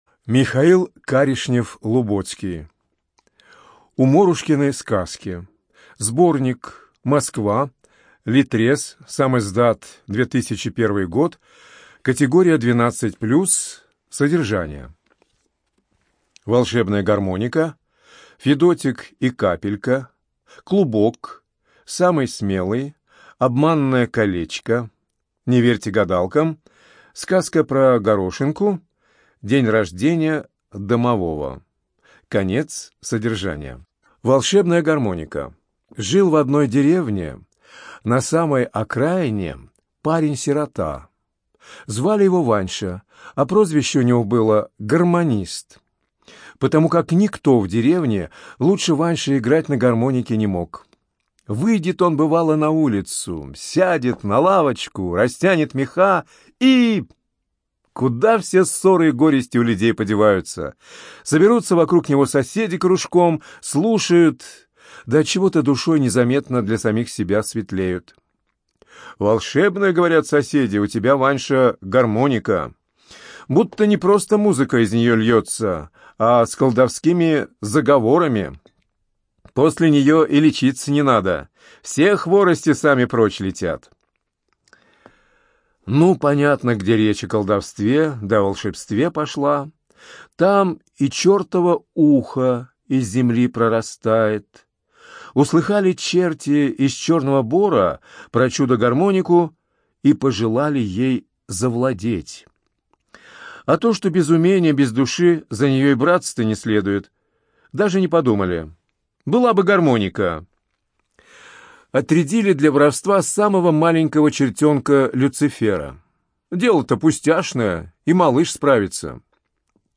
ЖанрСказки
Студия звукозаписиЛогосвос